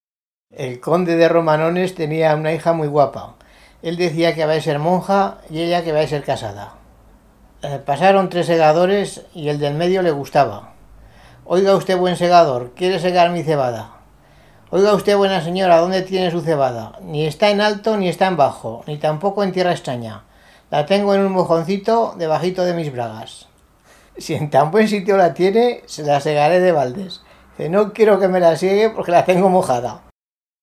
Clasificación: Romancero
Localidad: Jalón de Cameros